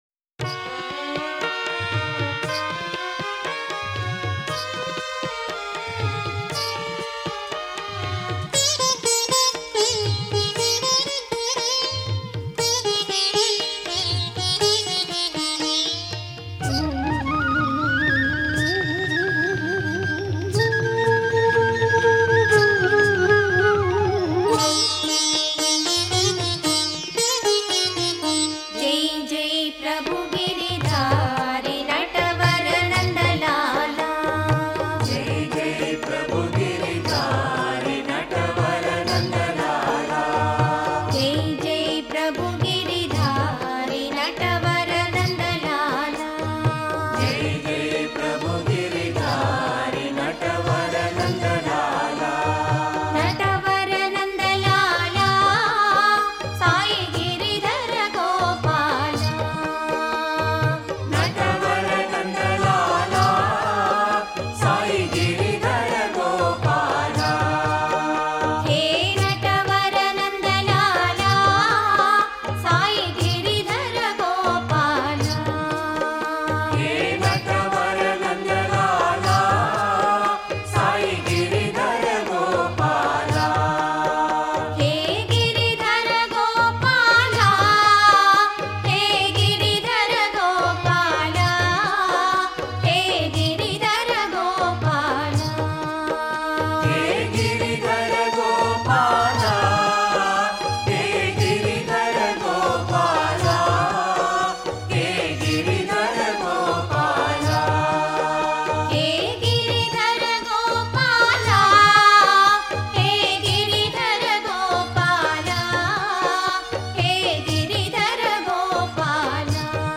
Home | Bhajan | Bhajans on various Deities | Krishna Bhajans | 32 JAI JAI PRABHU GIRIDHARI